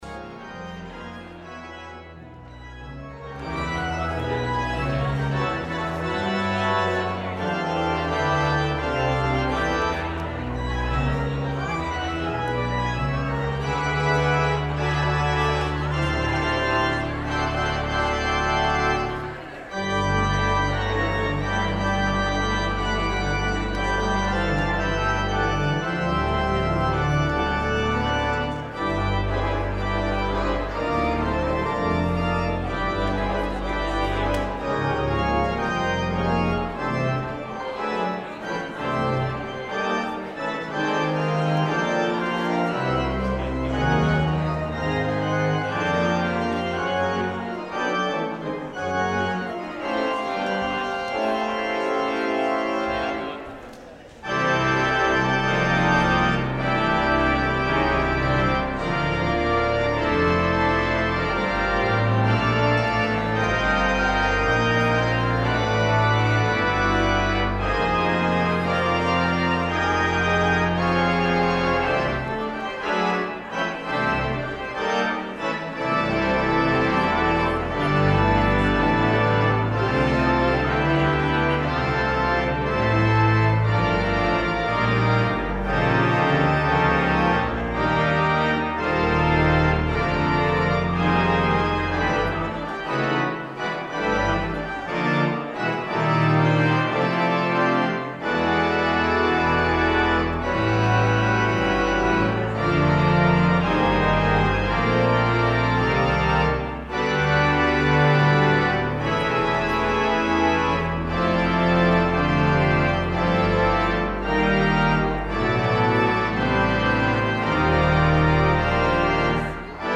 Fanfare and Chorale Calvin Fuller
organ